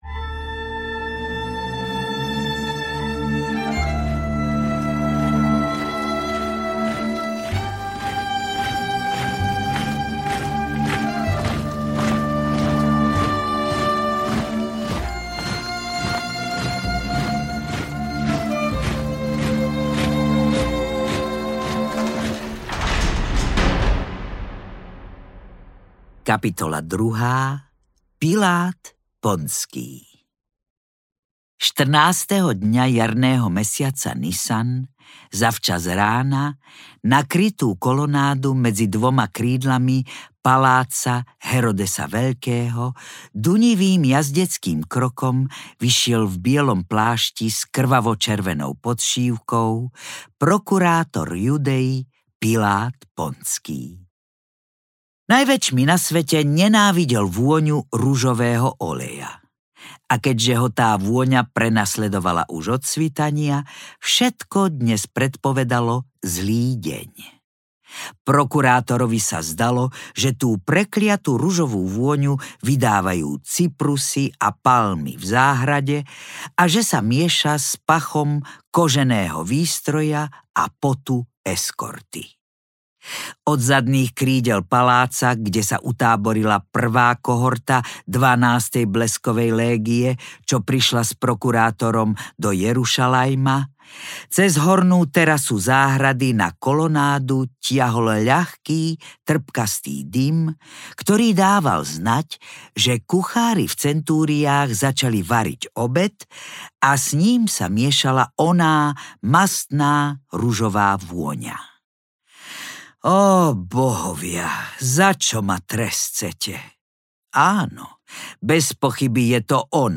Majster a Margaréta audiokniha
Ukázka z knihy
• InterpretZuzana Kronerová